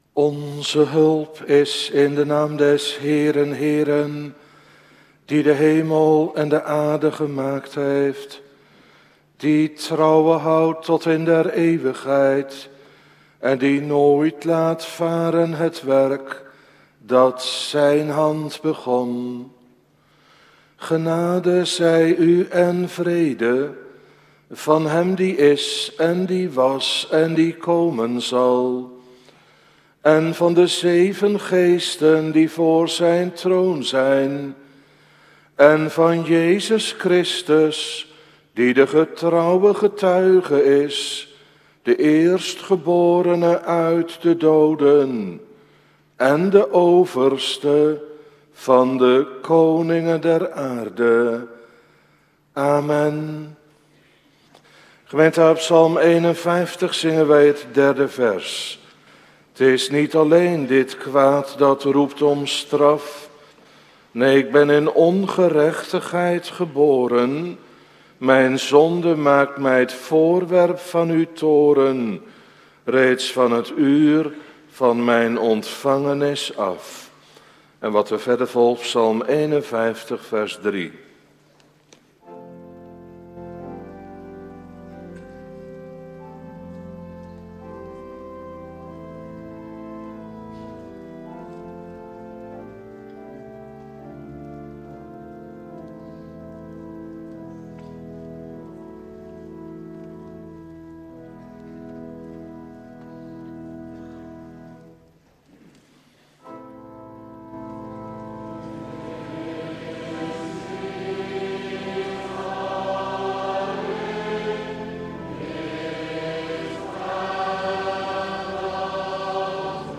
Preken terugluisteren